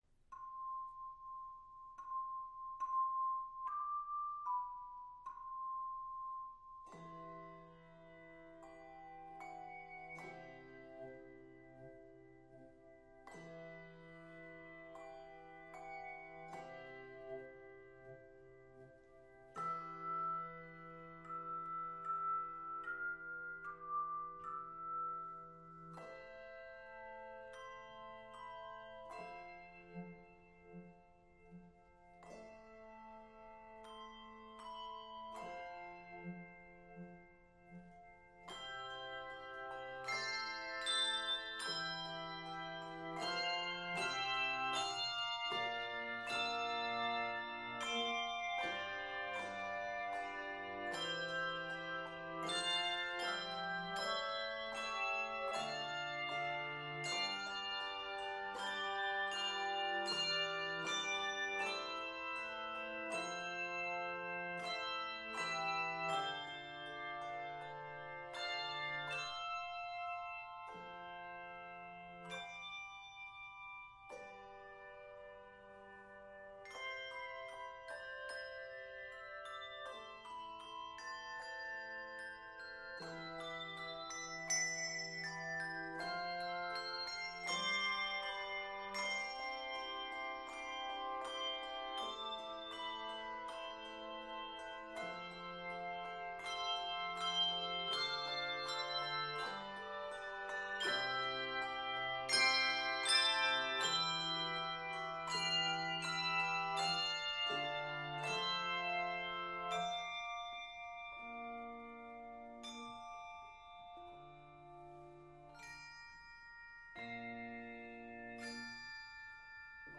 Keys: C Major and D major Uses